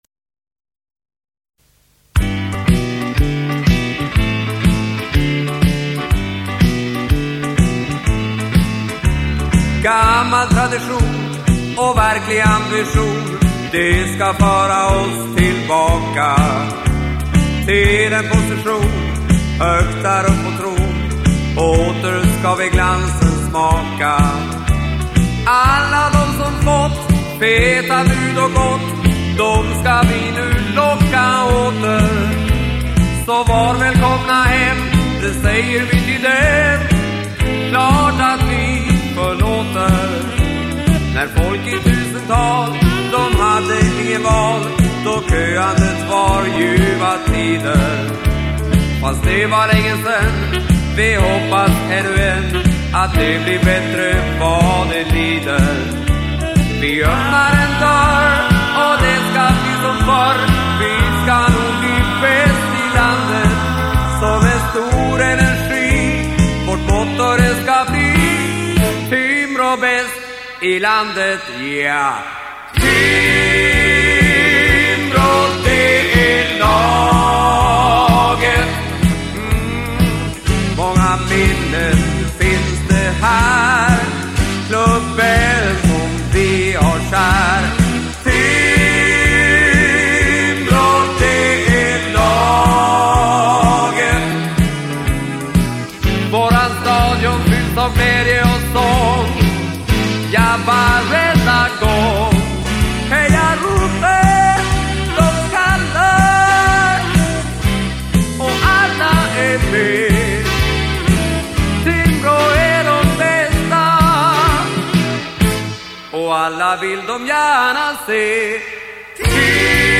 dansbandet